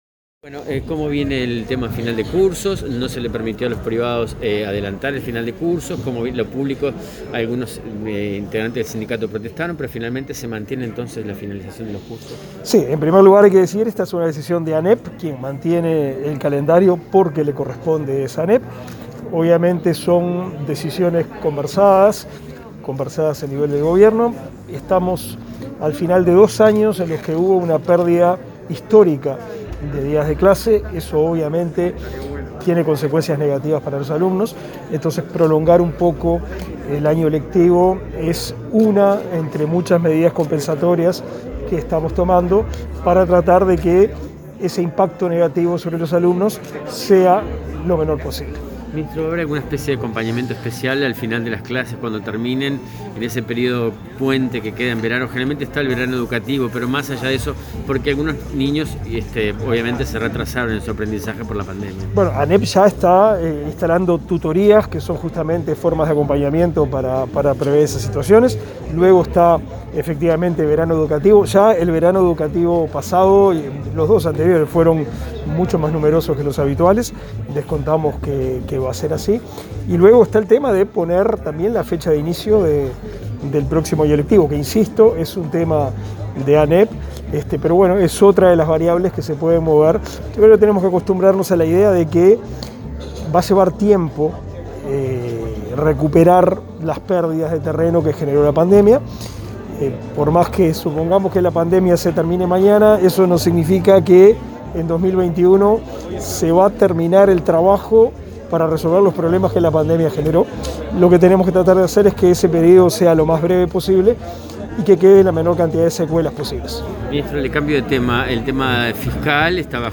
Declaraciones a la prensa del ministro de Educación y Cultura, Pablo da Silveira
Declaraciones a la prensa del ministro de Educación y Cultura, Pablo da Silveira 08/10/2021 Compartir Facebook X Copiar enlace WhatsApp LinkedIn Tras participar en el segundo encuentro de egresados becados por el Fondo Carlos Quijano, este 8 de octubre, el ministro de Educación y Cultura, Pablo da Silveira, efectuó declaraciones a la prensa.